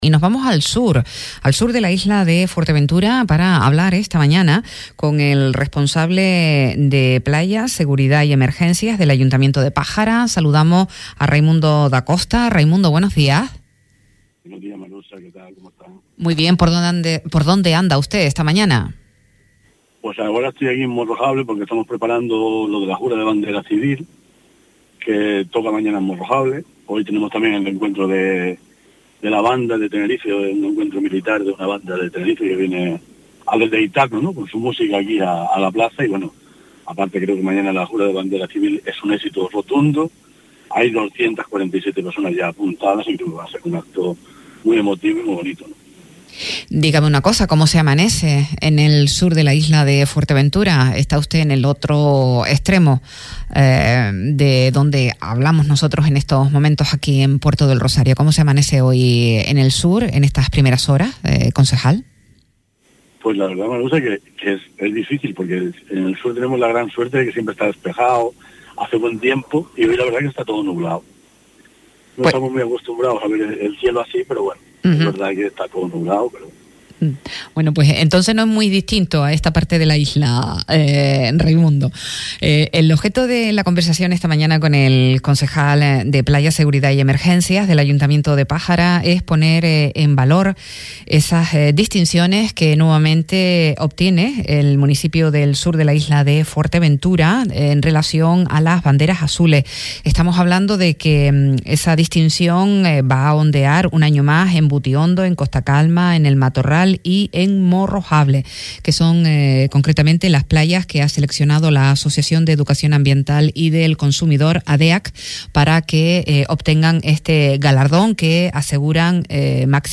A Primera Hora, entrevista a Raimundo Dacosta, concejal del Ayuntamiento de Pájara – 10.05.24
Entrevistas